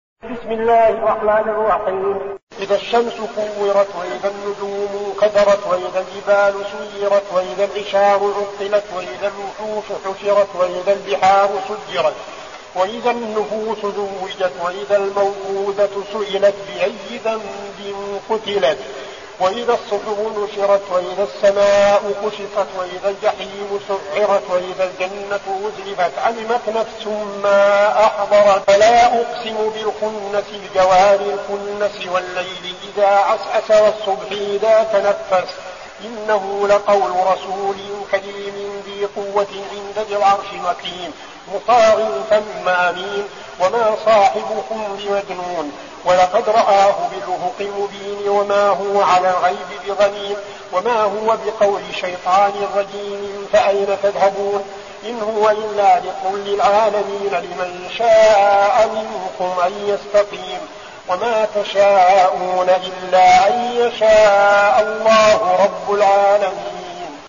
المكان: المسجد النبوي الشيخ: فضيلة الشيخ عبدالعزيز بن صالح فضيلة الشيخ عبدالعزيز بن صالح التكوير